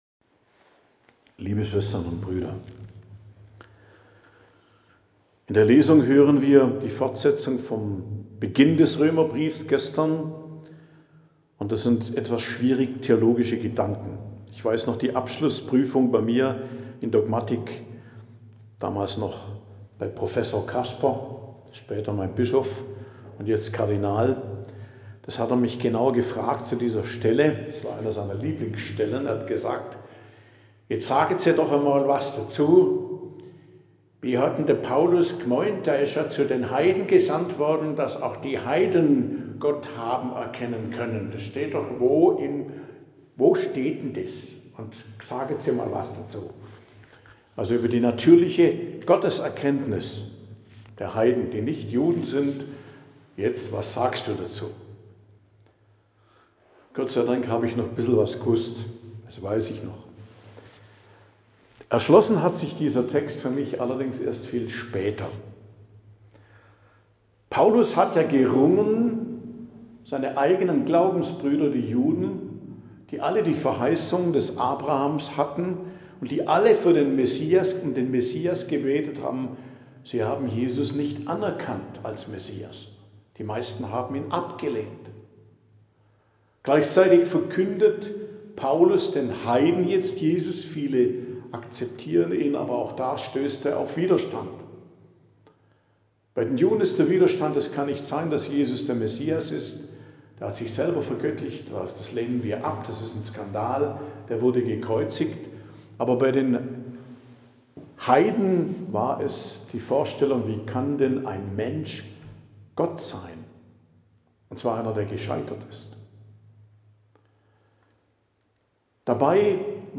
Predigt am Dienstag der 28. Woche i.J., 14.10.2025